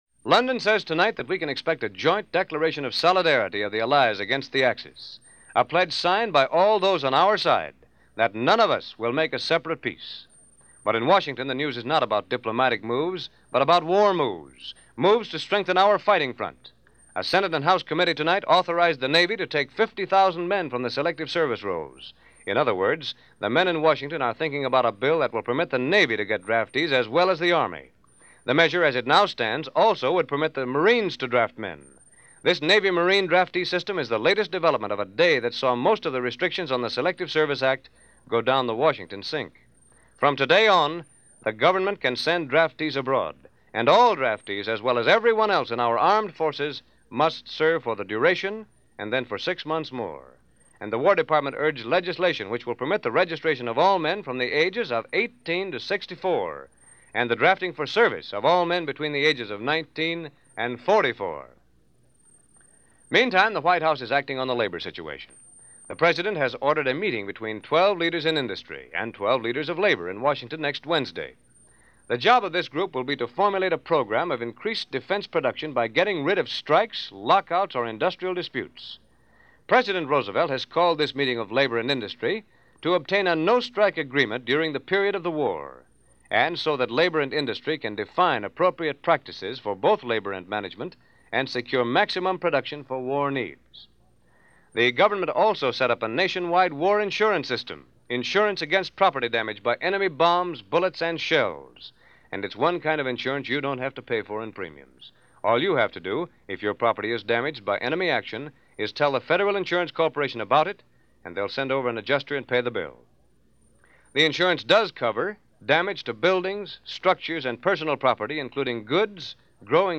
December 13, 1941 – News Reports of the day – NBC – Red And Blue Networks – Gordon Skene Sound Collection –